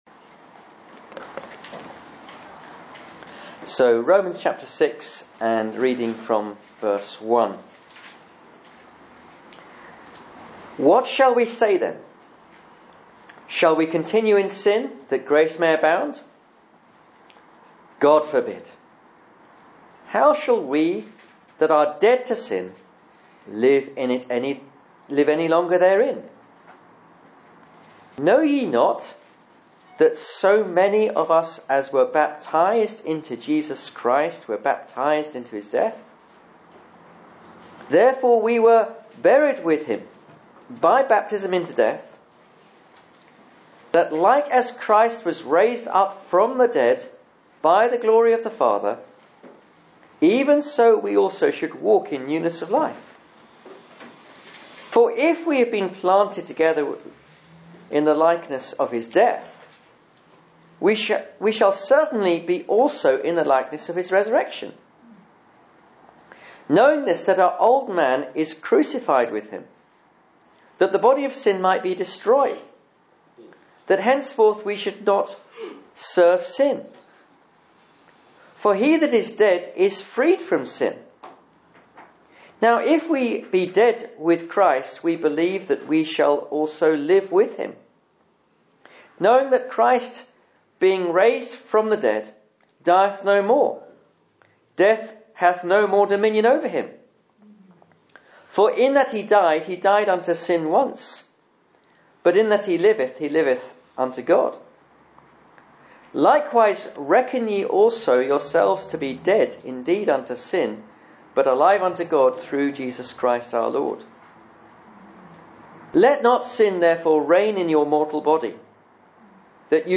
Romans 6:1 Service Type: Sunday Evening Service « ‘The Lust of the Eyes